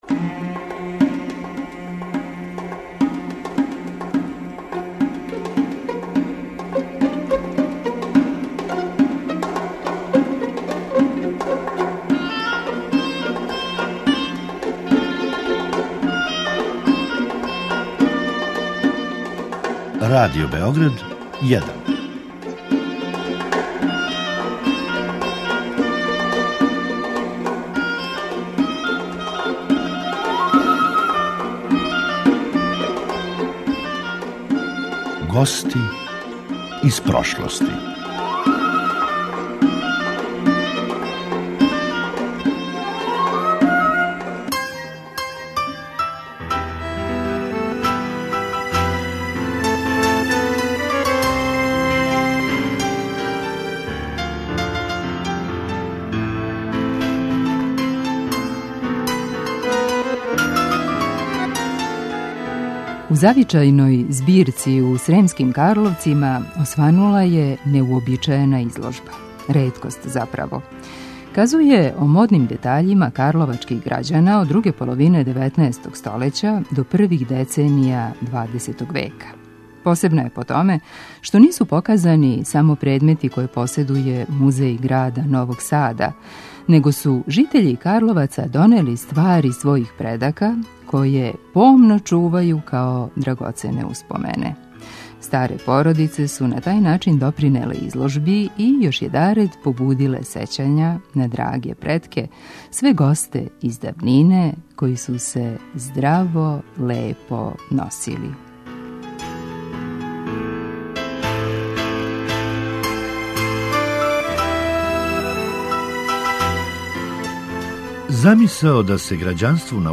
Са музејским кустосима и њиховим гошћама разговарао